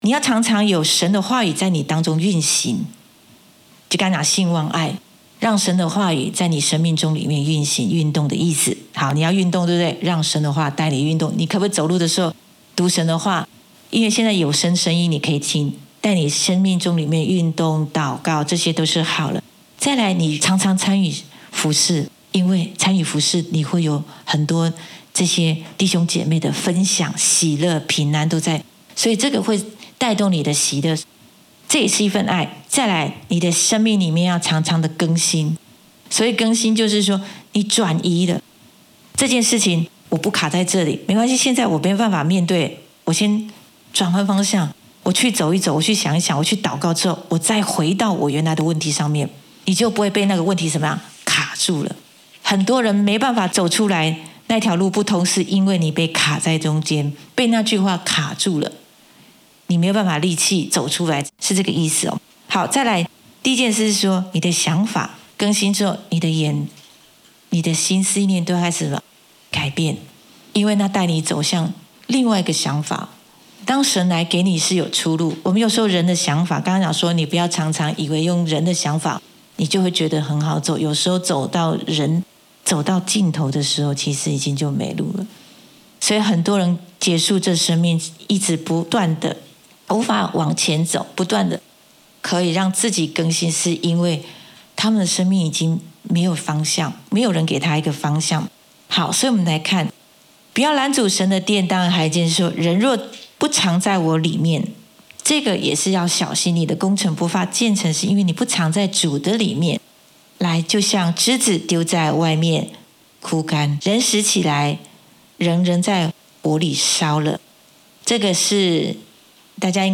in 主日信息